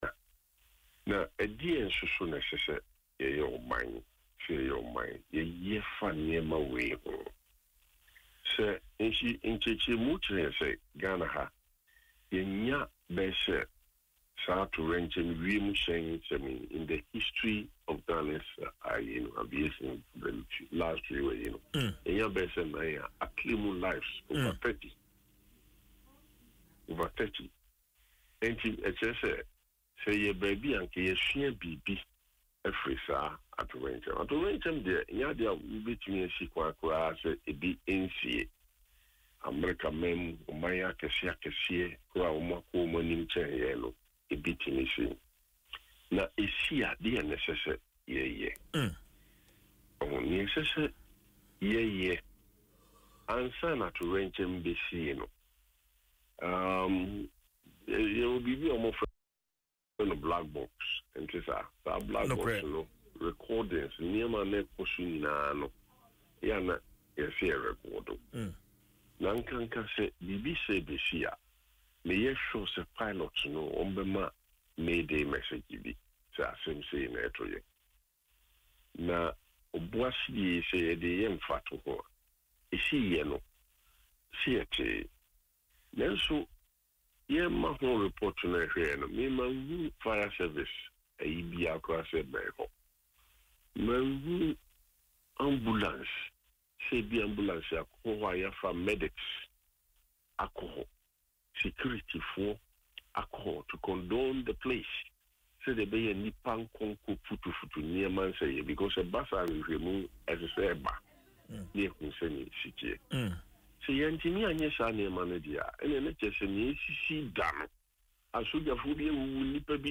In an interview on Adom FM’s morning show Dwaso Nsem, he stressed the need for Ghana to learn from such disasters.